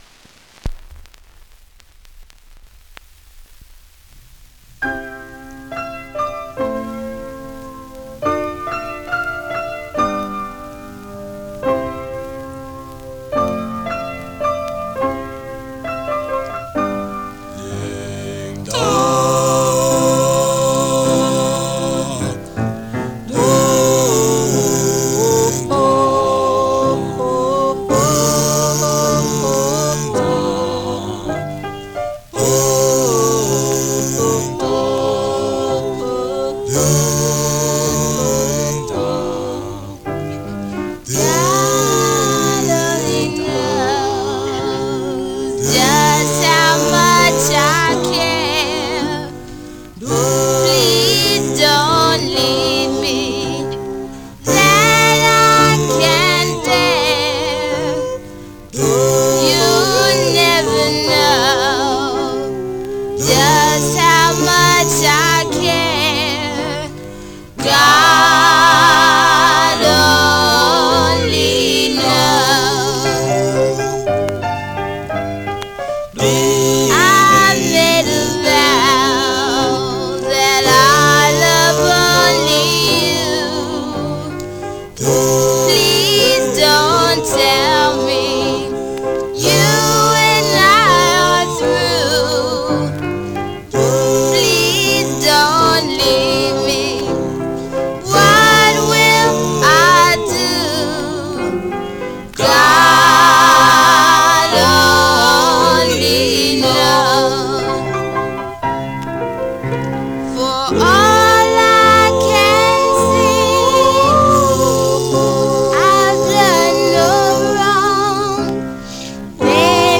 Condition: VG+ slite warp,nap Classic Philadelphia
Some surface noise/wear
Mono
Male Black Groups